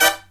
HIGH HIT08-L.wav